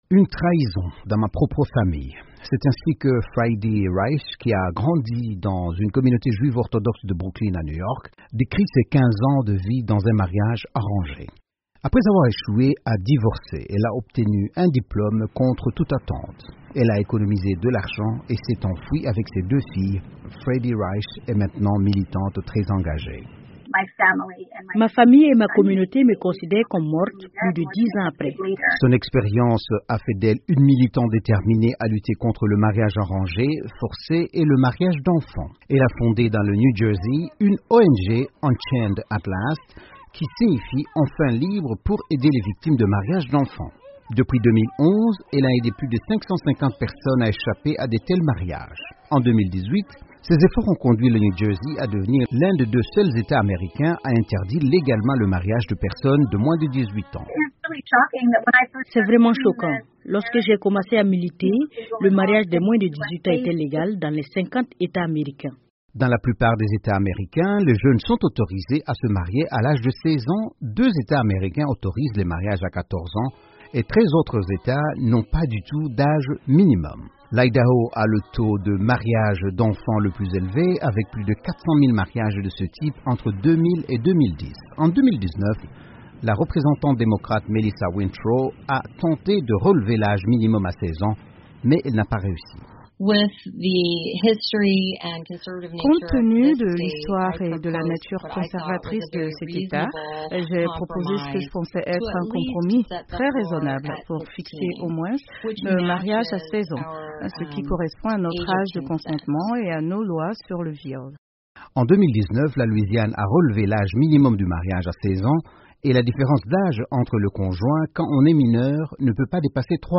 Mais pour des centaines de milliers de jeunes filles ici aux Etats Unis, l'idée de mariage est souvent synonyme de cauchemar à cause du mariage des enfants. Le reportage